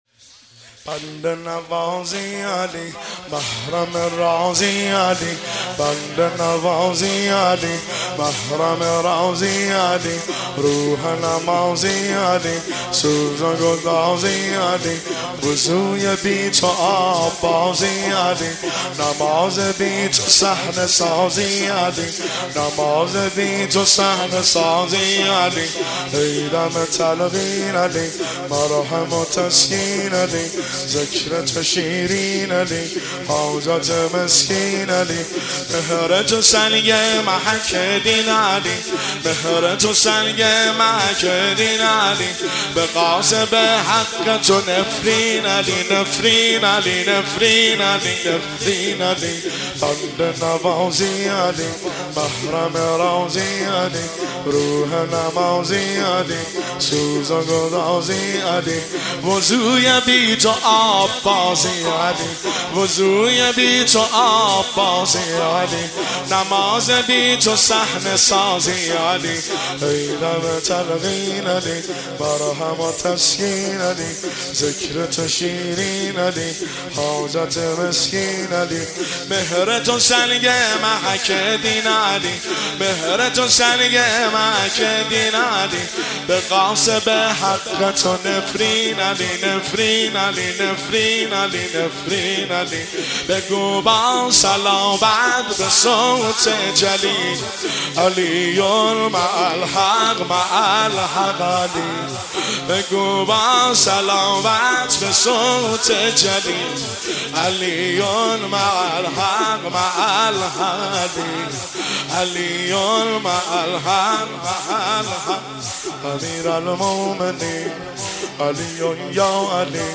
تک  شب چهارم محرم الحرام 1404
دانلود تک شب 4 محرم 1404